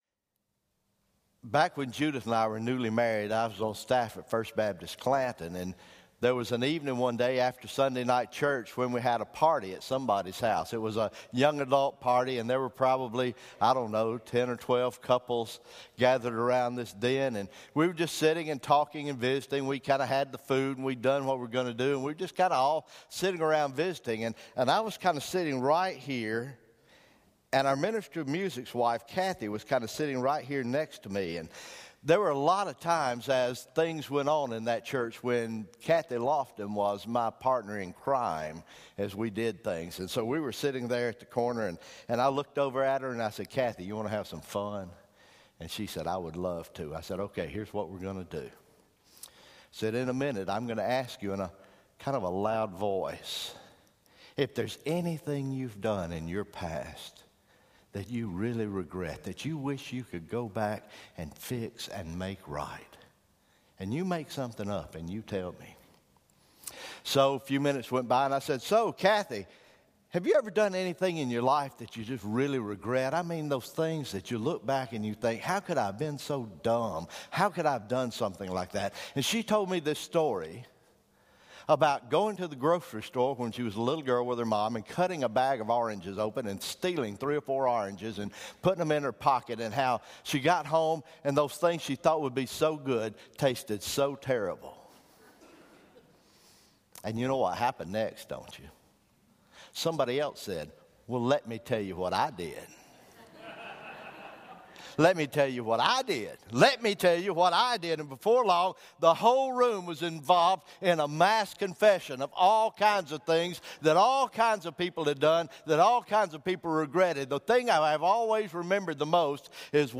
January 29, 2017 Morning Worship